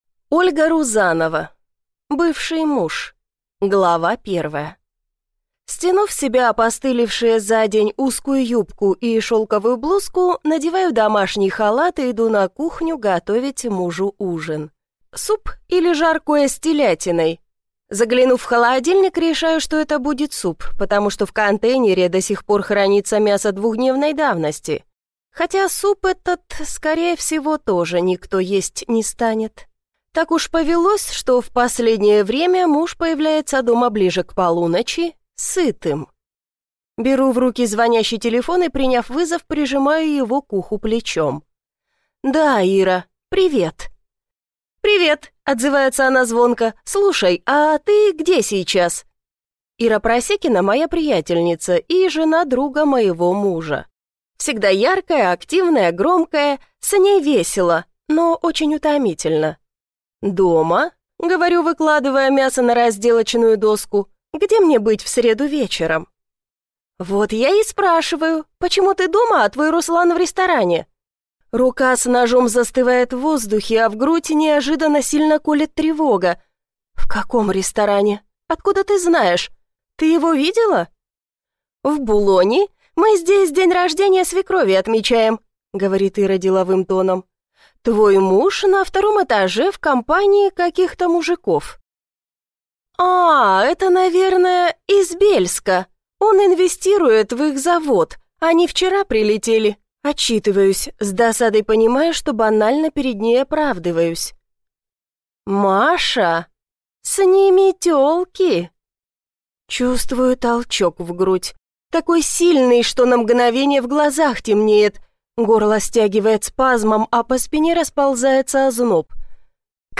Аудиокнига Бывший муж | Библиотека аудиокниг